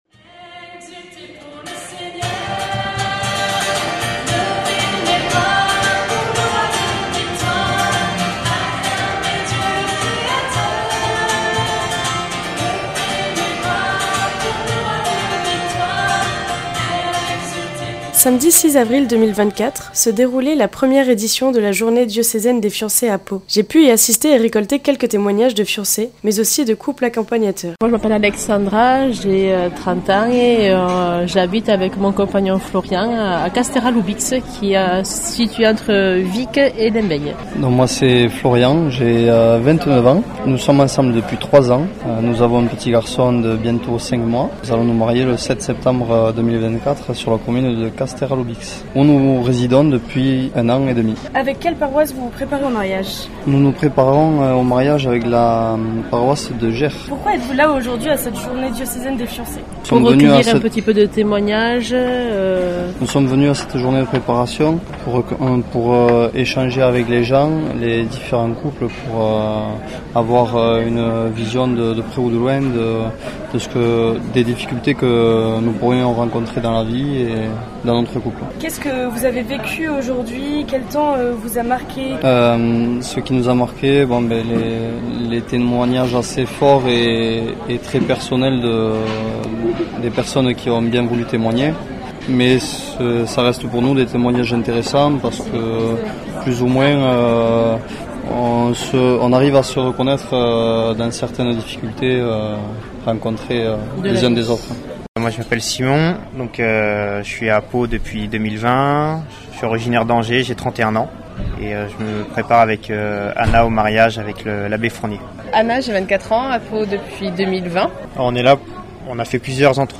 Voici quelques témoignages recueillis lors de cette 1ère édition de la Journée diocésaine des fiancés proposée par le Service diocésain de la Pastorale Familiale.